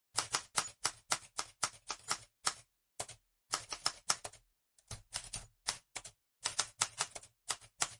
typewriter.mp3